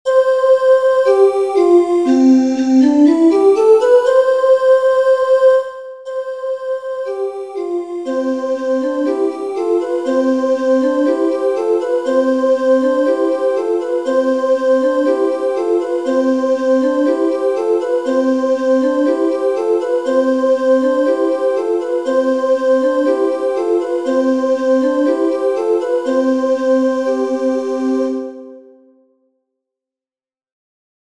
Kanon zu drei Stimmen